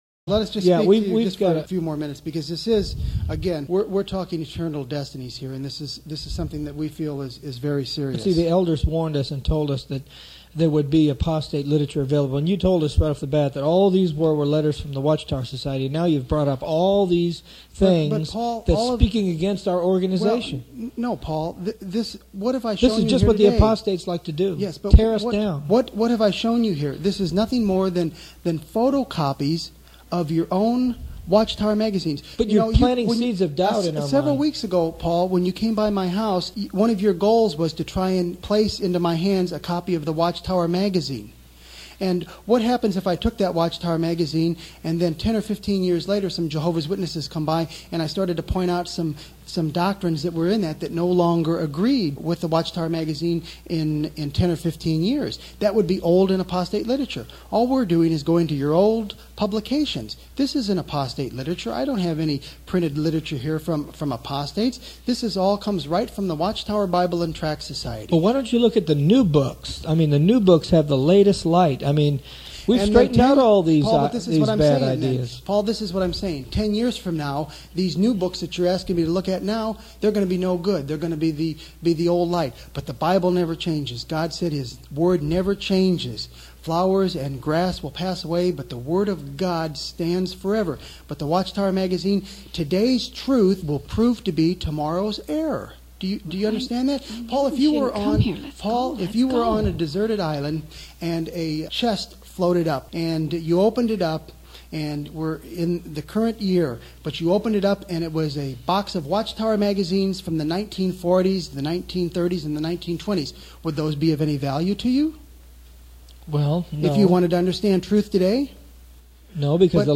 This series contains four compact disks (CDs) which teach the Christian believer how to defend his faith by refuting major theological errors of the Jehovah’s Witnesses through a realistic dialogue.
• Insight into a Christian/Jehovah’s Witness dialogue.